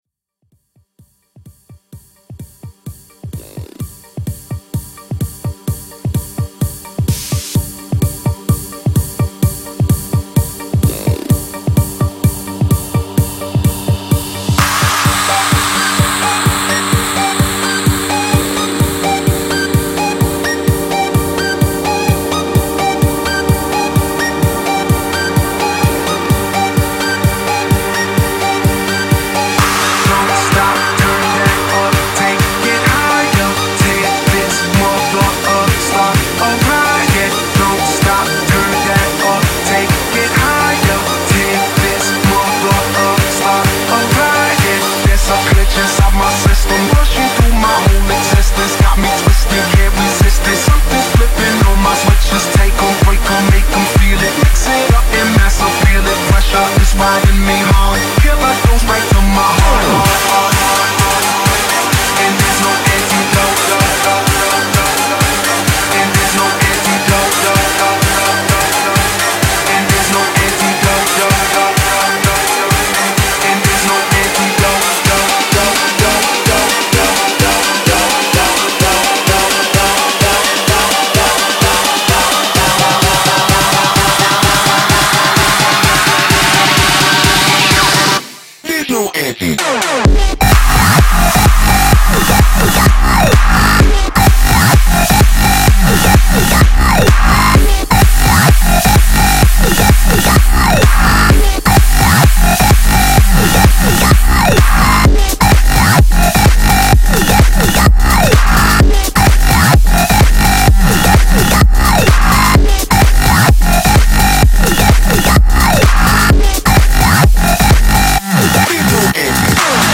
BPM128-256
Audio QualityLine Out